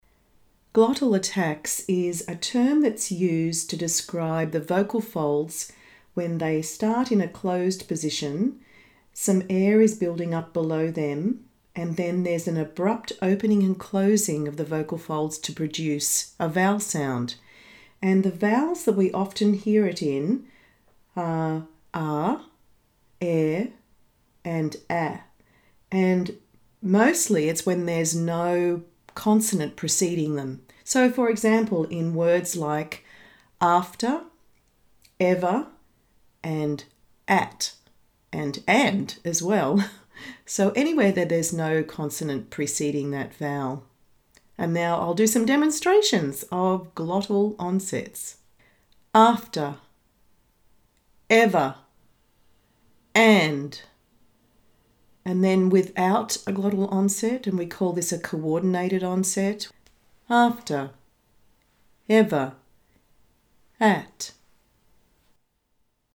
• Pressed phonation
Glottal attacks